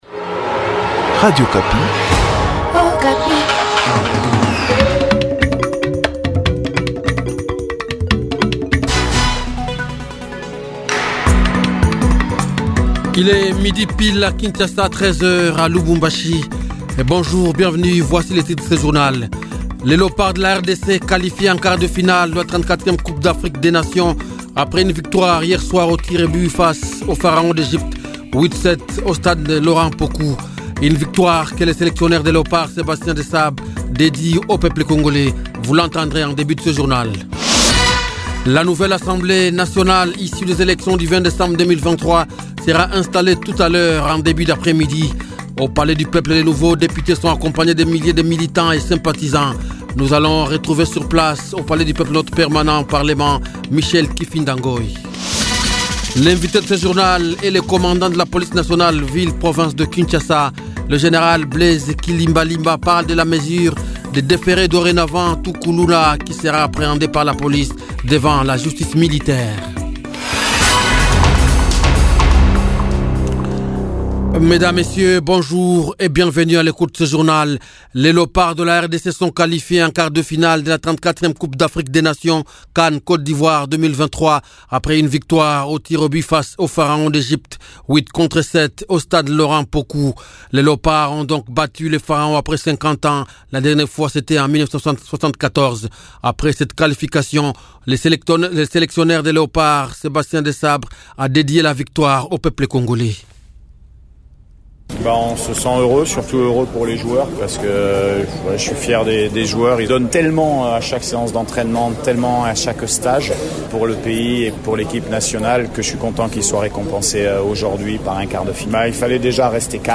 vox pop